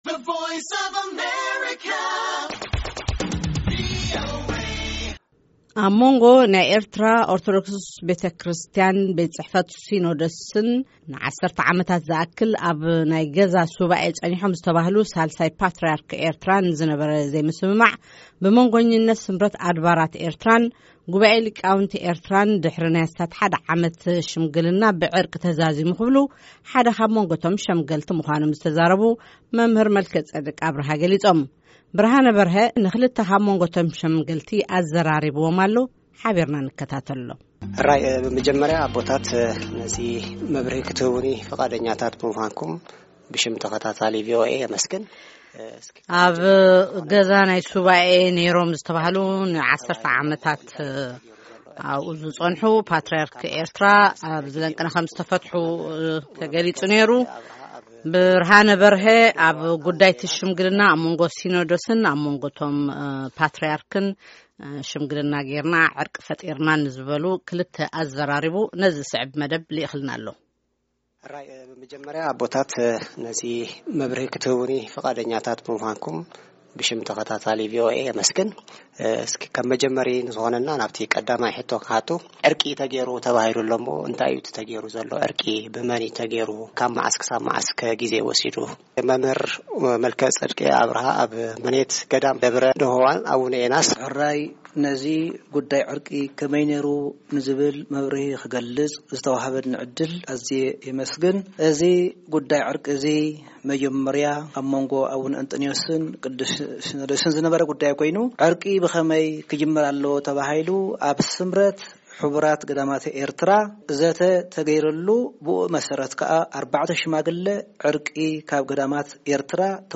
ዝርዝር ቃለ መጠይቕ - ኣብ ጉዳይ ሳልሳይ ፓትርያርክ ኦርቶዶክስ ተዋህዶ ቤተ ክርስትያን ኤርትራ